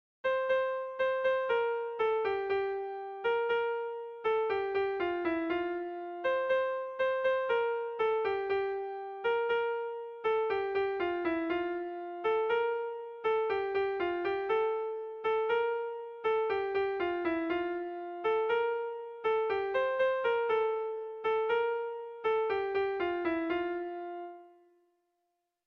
Sentimenduzkoa
Zortzi puntuko berdina, 10 silabaz
AAB1B2